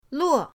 luo4.mp3